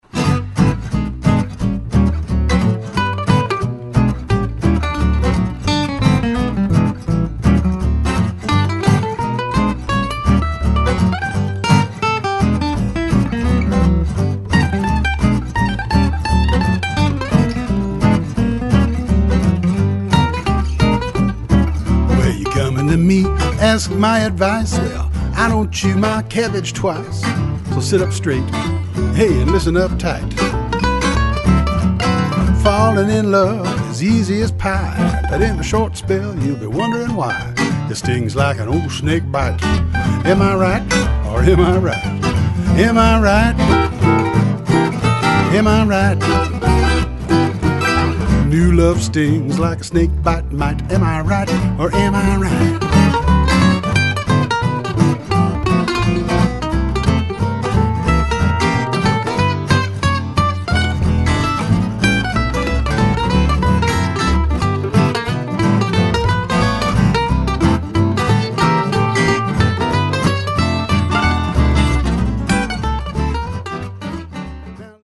came by to play upright bass on most of the tracks.